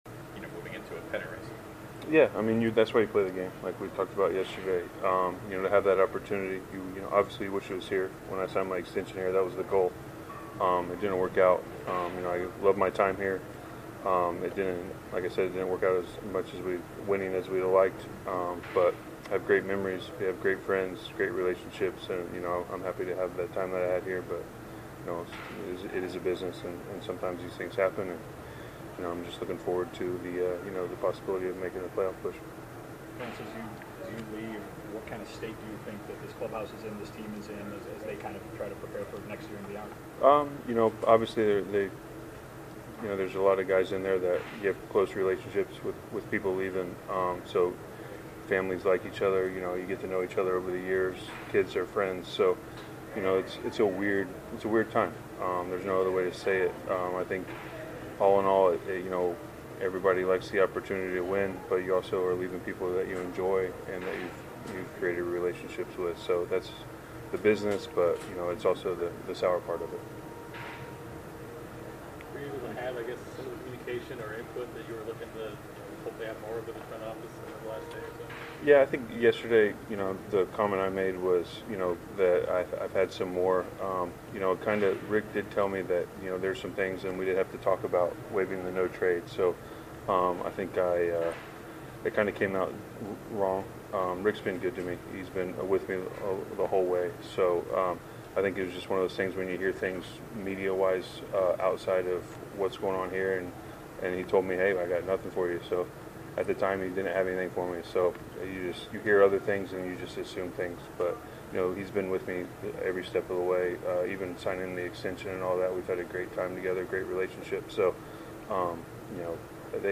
Chicago White Sox Lance Lynn address the media after being traded to the Los Angeles Dodgers